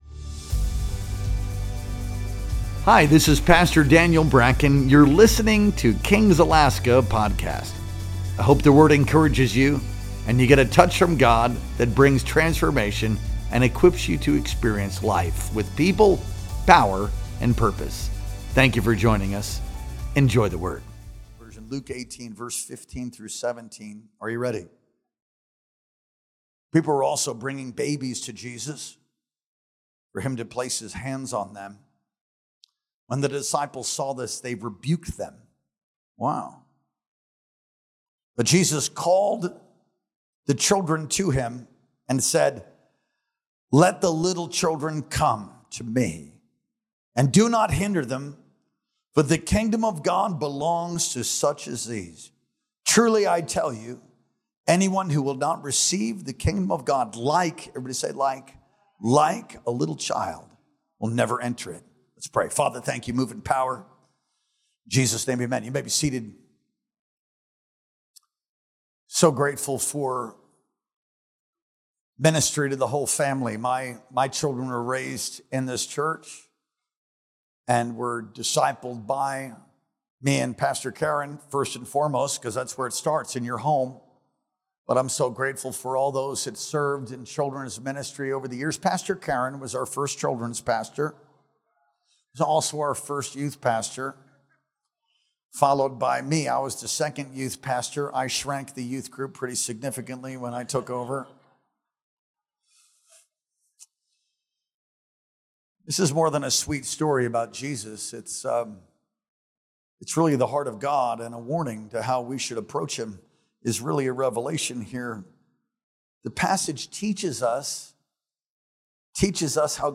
Our Sunday Night Worship Experience streamed live on September 21st, 2025.